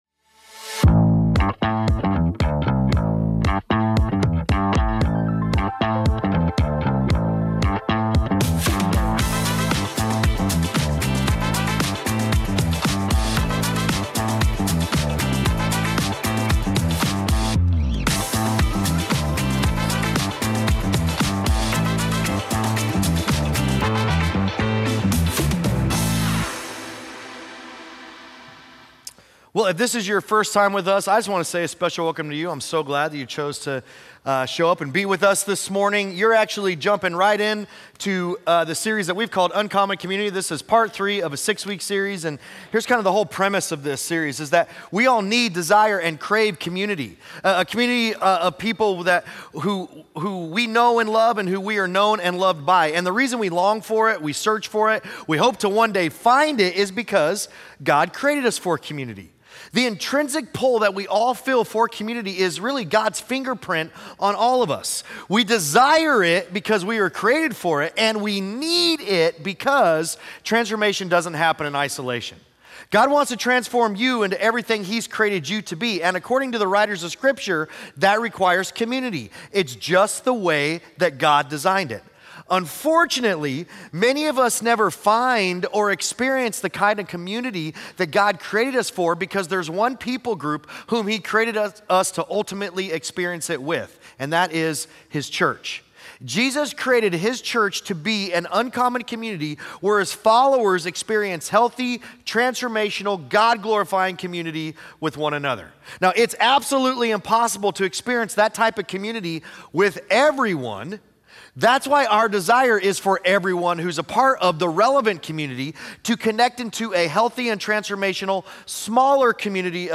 Weekly sermons from Relevant Community Church in Elkhorn, NE.
Sunday Sermons